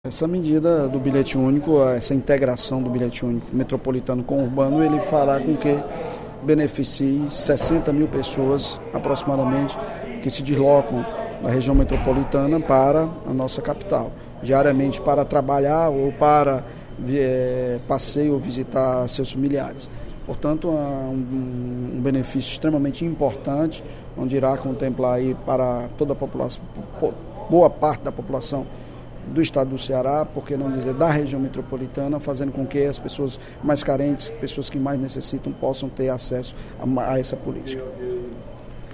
O líder do governo na Assembleia Legislativa do Ceará, deputado Evandro Leitão (PDT), destacou, no primeiro expediente da sessão plenária desta terça-feira (26/04), o lançamento do Bilhete Único Metropolitano pelo Governo do Estado, no último sábado (23/04).